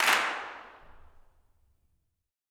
CLAPS 11.wav